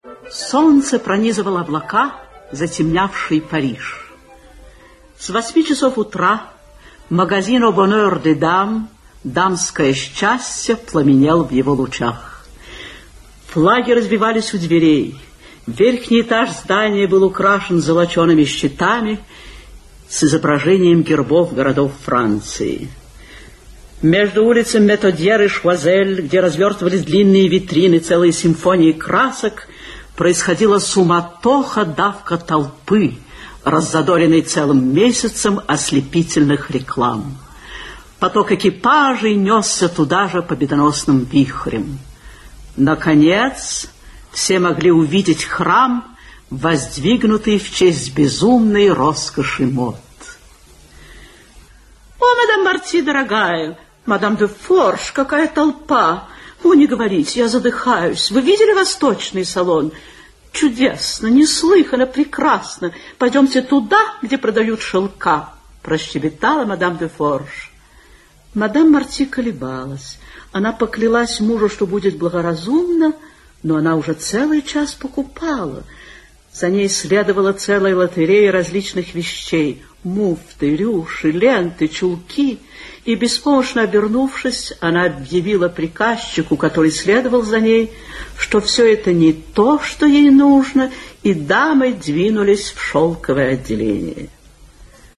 Aудиокнига Рассказы Автор Эмиль Золя Читает аудиокнигу Виталий Полицеймако.